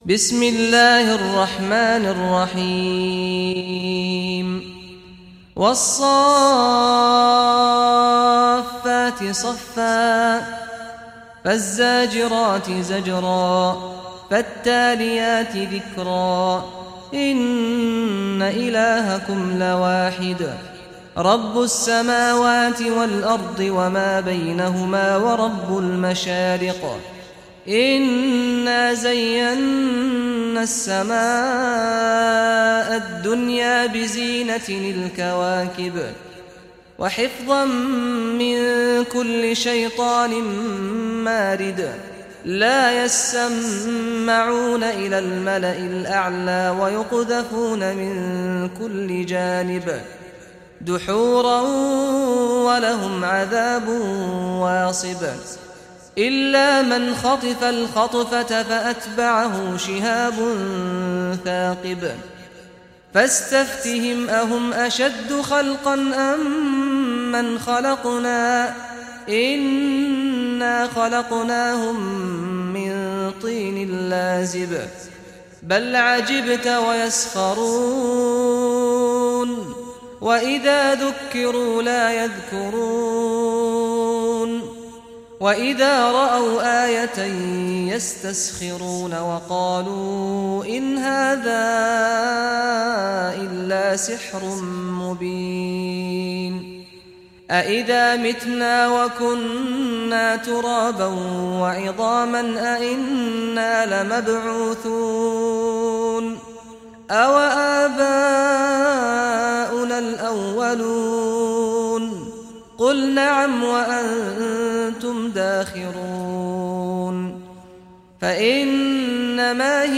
Surah As-Saffat Recitation by Sheikh Saad Ghamdi
Surah As-Saffat, listen or play online mp3 tilawat / recitation in Arabic in the beautiful voice of Sheikh Saad al Ghamdi.